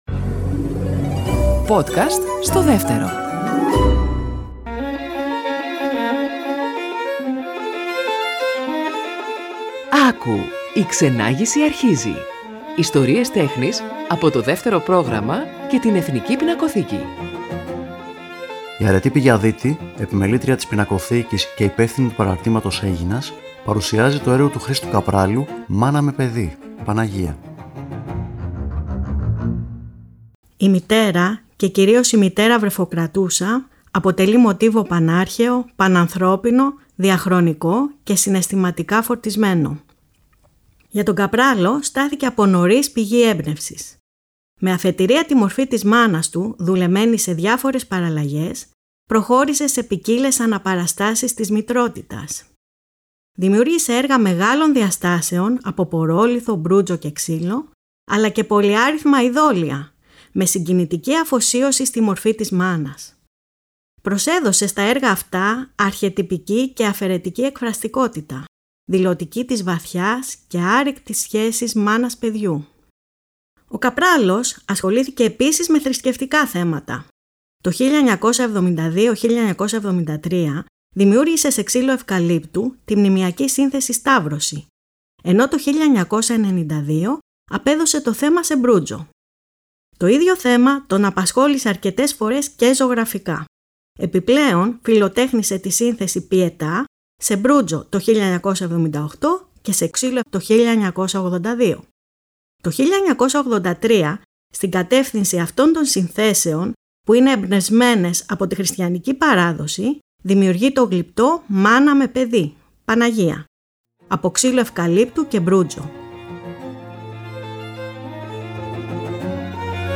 Κείμενο / Αφήγηση